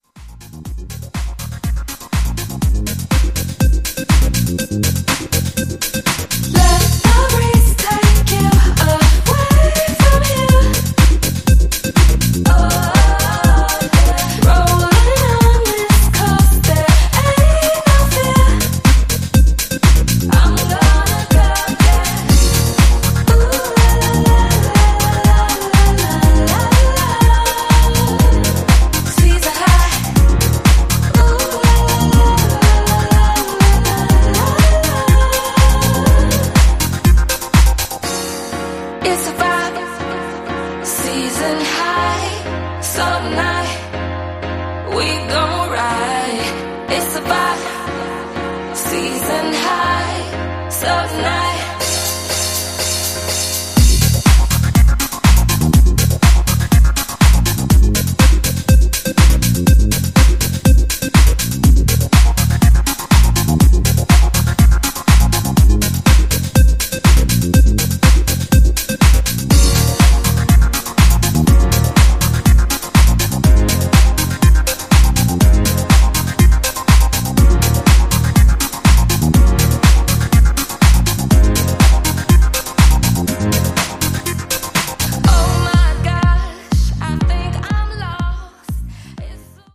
This package delivers two quality soulful house cuts